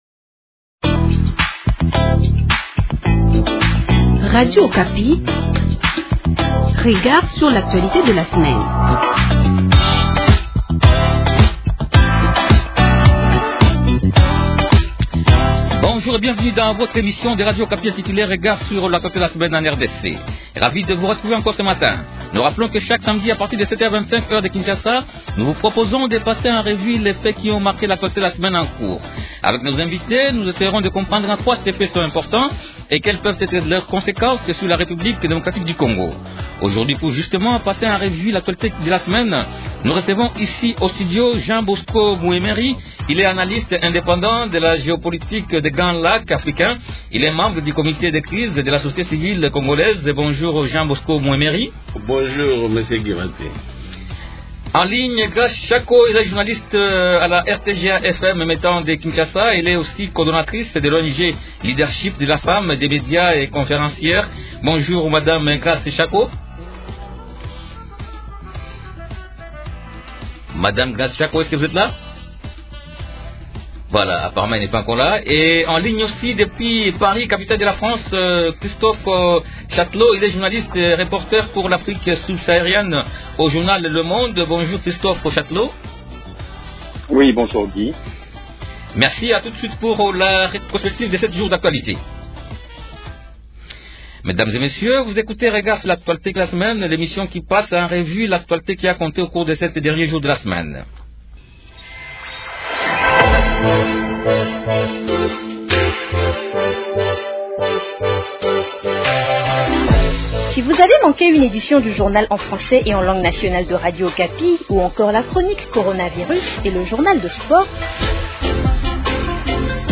Actualités politiques de ce soir